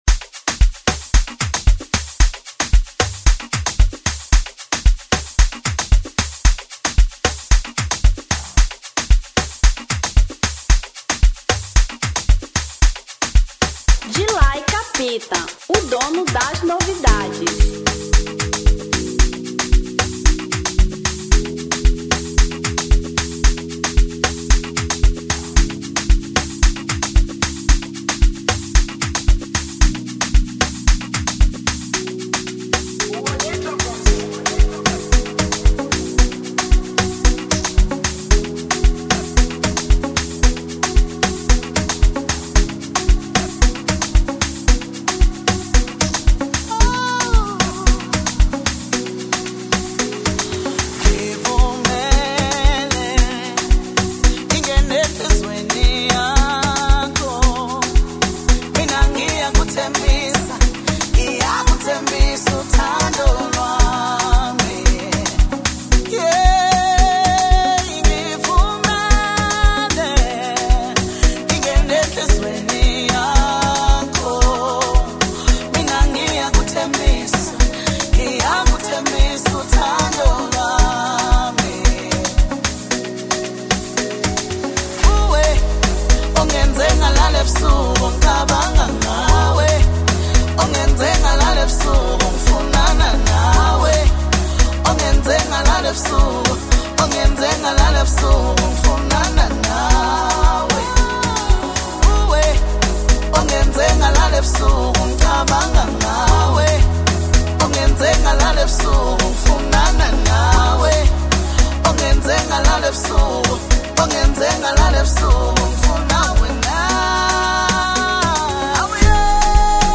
Amapiano 2025